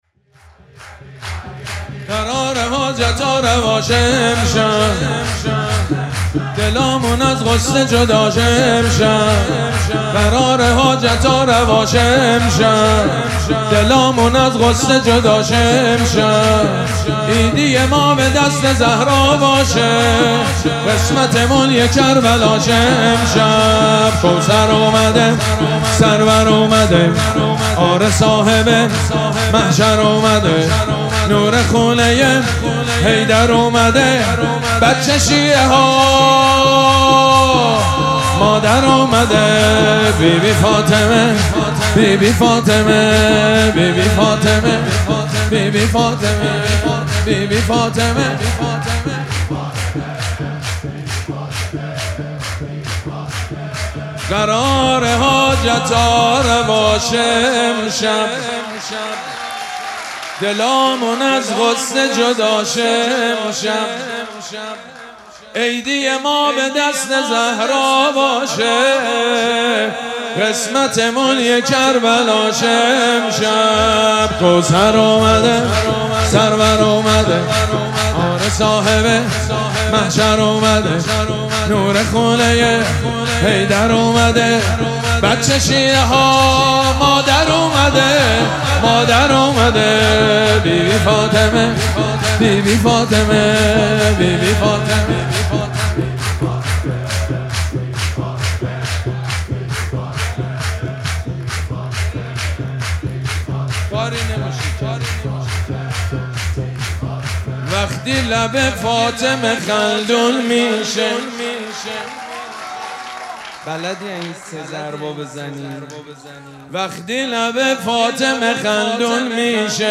مراسم جشن ولادت حضرت زهرا سلام الله علیها
سرود
مداح
حاج سید مجید بنی فاطمه